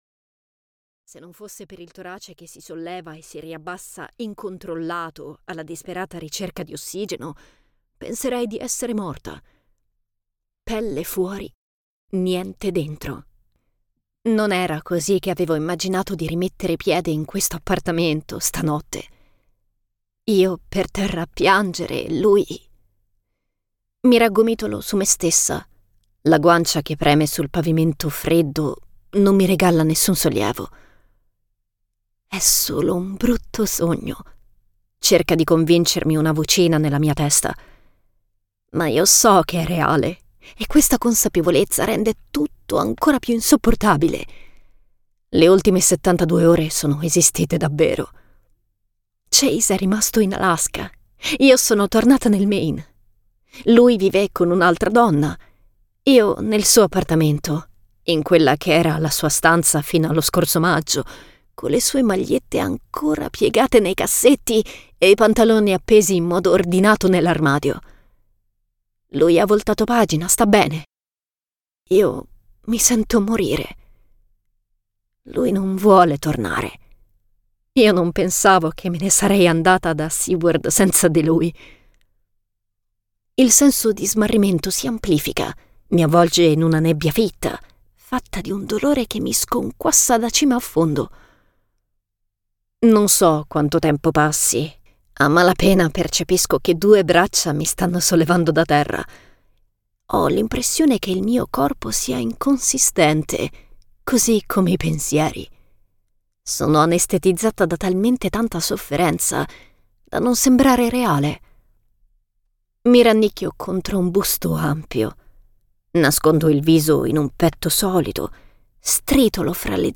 "Before Dawn" di Valentina Ferraro - Audiolibro digitale - AUDIOLIBRI LIQUIDI - Il Libraio